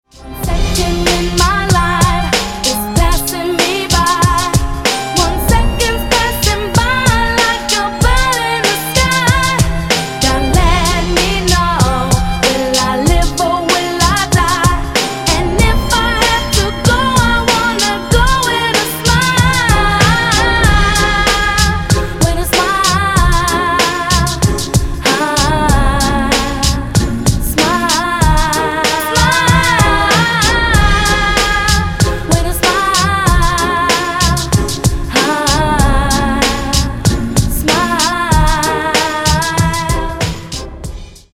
• Качество: 192, Stereo
женский вокал
dance
90-е
vocal